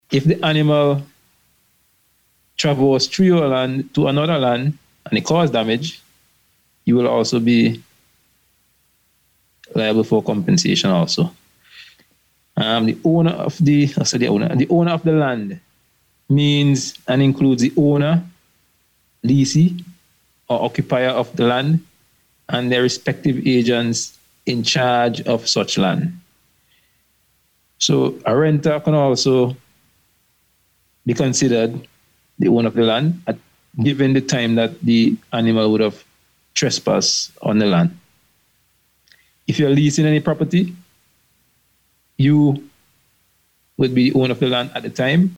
Speaking on the Police on the Beat programme on NBC Radio